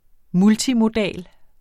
Udtale [ ˈmultimoˌdæˀl ]